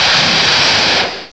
sovereignx/sound/direct_sound_samples/cries/kyurem.aif at master